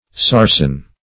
Search Result for " sarsen" : The Collaborative International Dictionary of English v.0.48: Sarsen \Sar"sen\, n. [Etymol. uncertain; perhaps for saracen stone, i.e., a heathen or pagan stone or monument.]